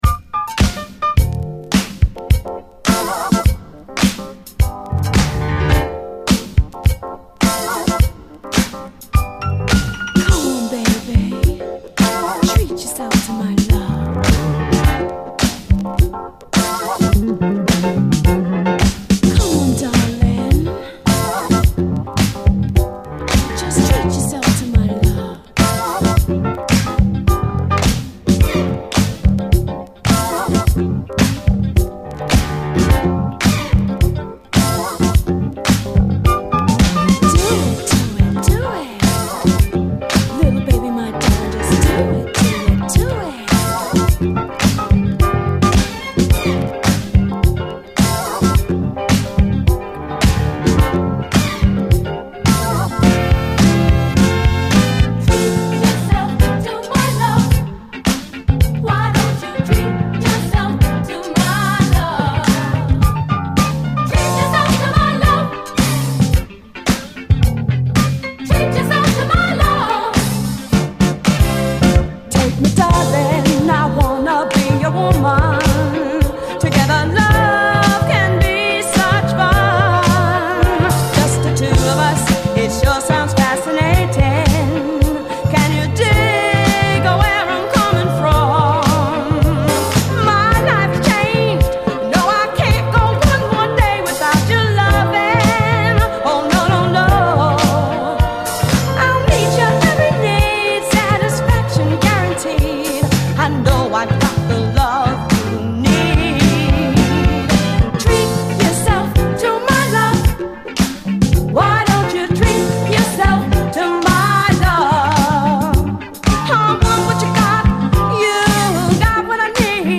SOUL, 70's～ SOUL, DISCO
アダルトなムードのメロウ・モダン・ブギー！
ヒタヒタと闇夜に忍び寄るようなイントロから渋く洒落た雰囲気、ガラージ好きに是非オススメしたい一曲！